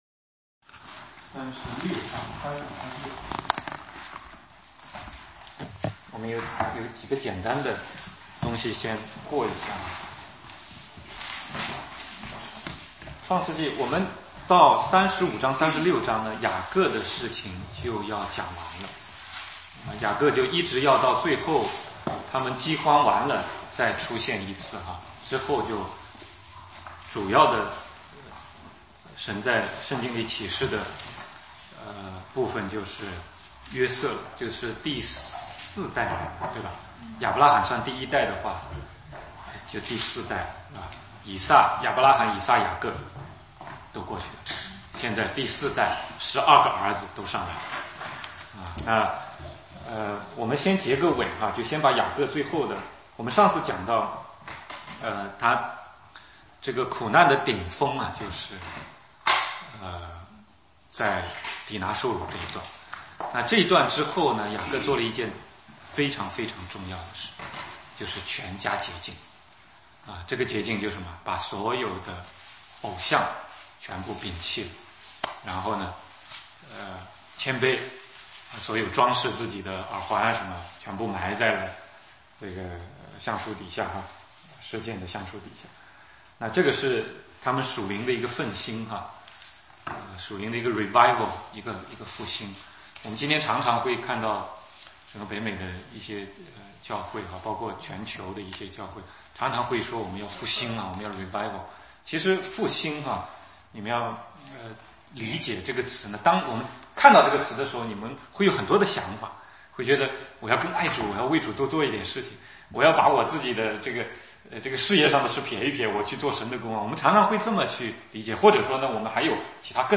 16街讲道录音 - 创世纪36-38 雅各的儿子们 犹大与她玛